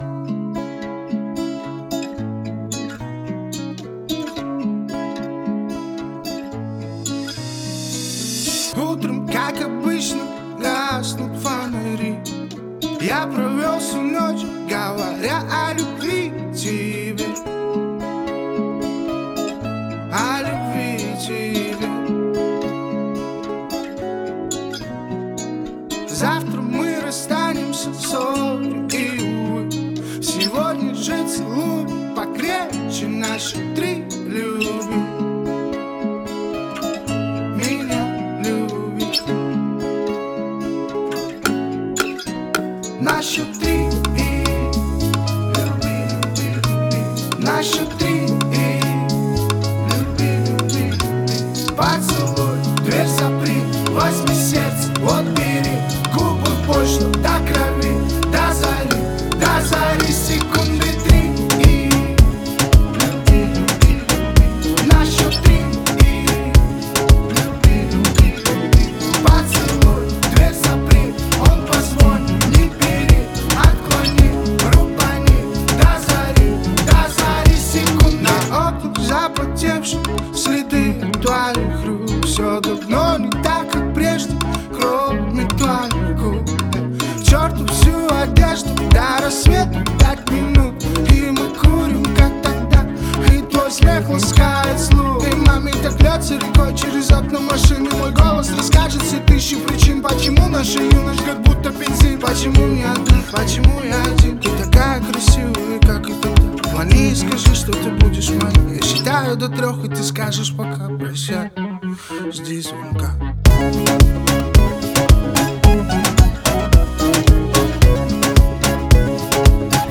зажигательная песня в жанре поп-музыки